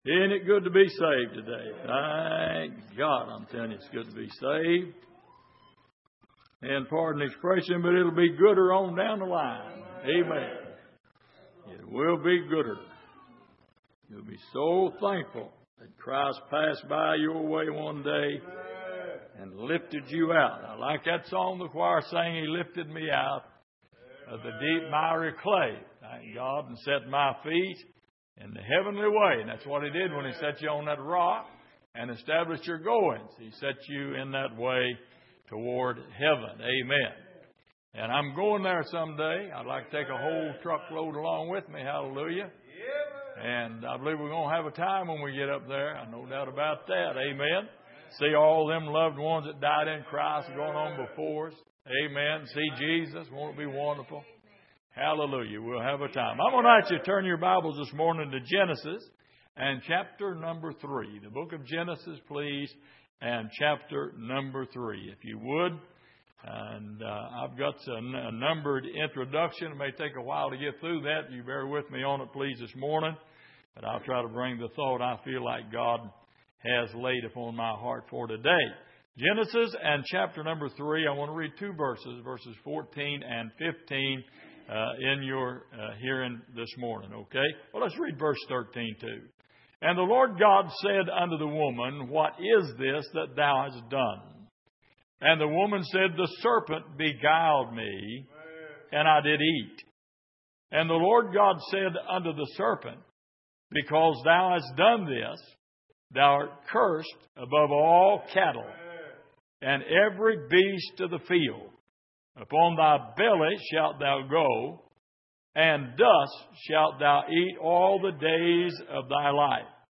Genesis 3:13-15 Service: Sunday Morning What’s Going On Between You And The Devil?